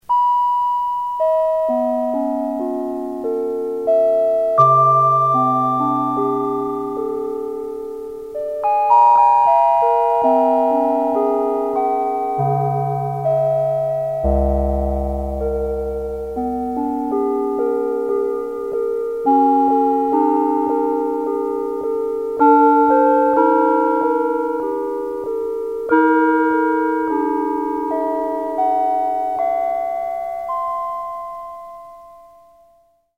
Microtonal scales
microtonal